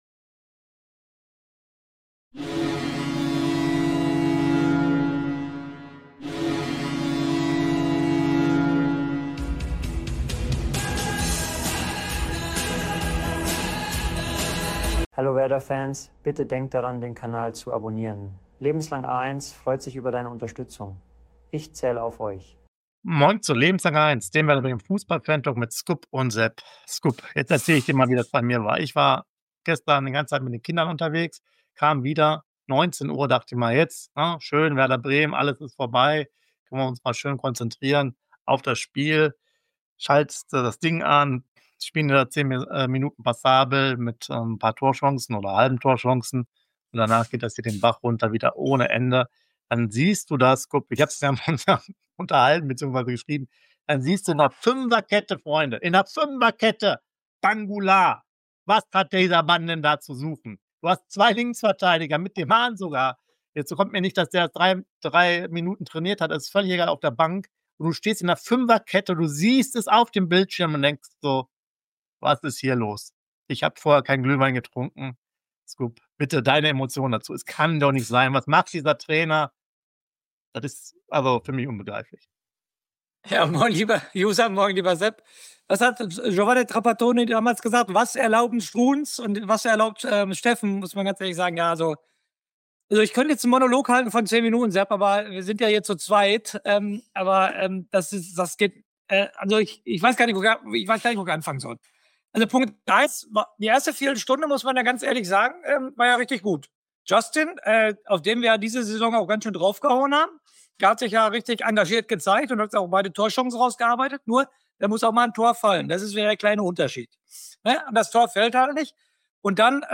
Dem Werder Bremen - Fantalk